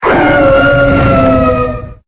Cri de Reshiram dans Pokémon Épée et Bouclier.